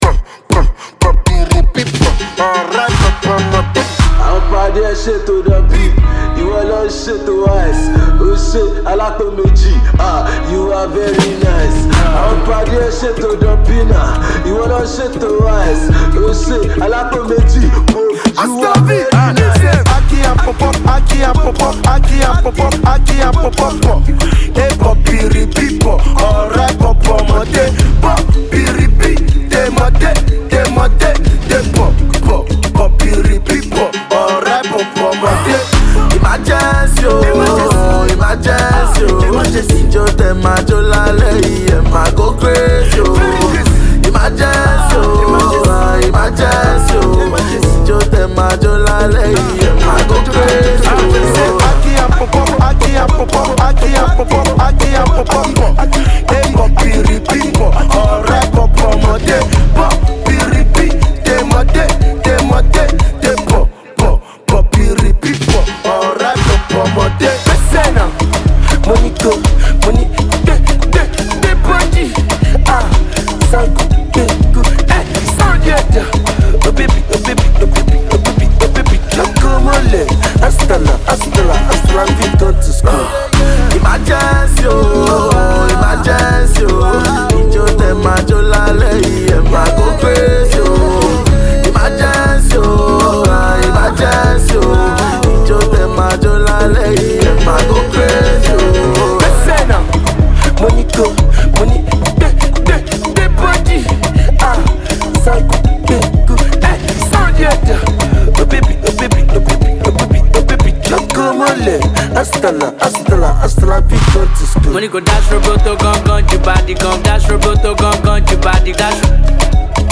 a new song from Zanku crooner